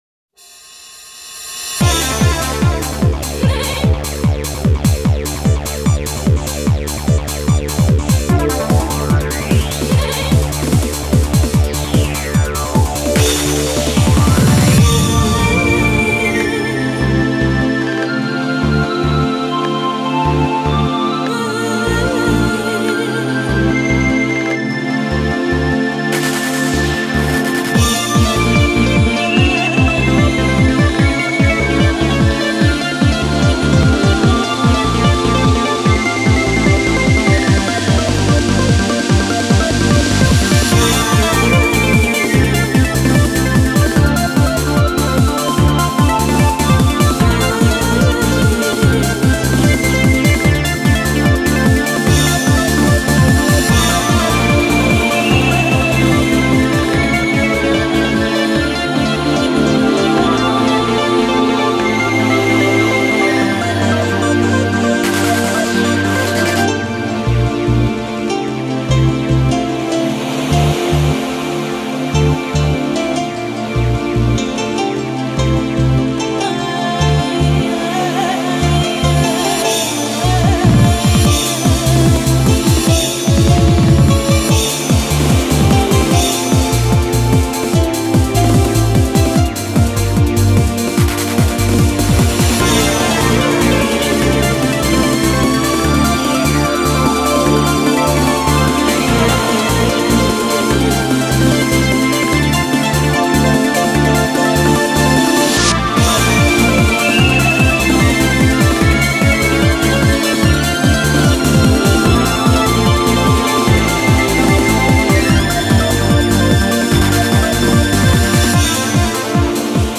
BPM148
Comments[DUTCH TRANCE]